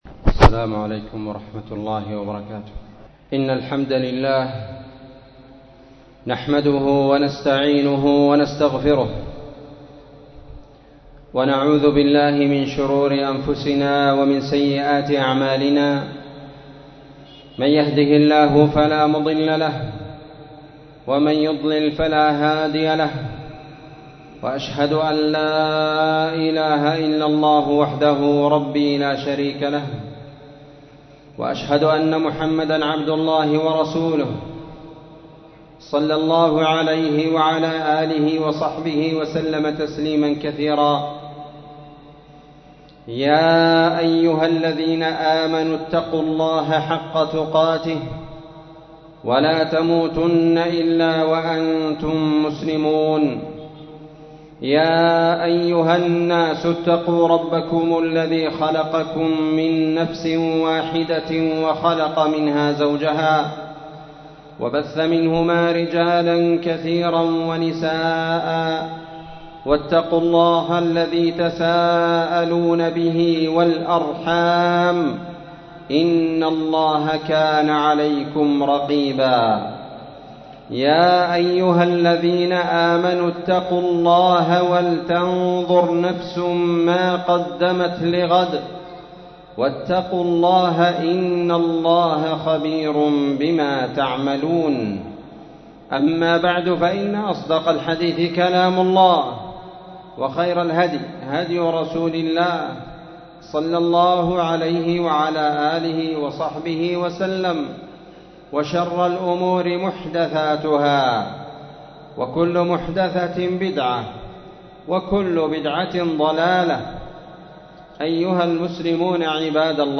عنوان اسم الله العظيم تاريخ النشر 2021-10-17 وصف خطبة الجمعة ????
مسجد المجاهد مسجد أهل السنة والجماعة تعز _اليمن ????